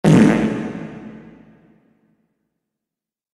fart echo
fart-echo.mp3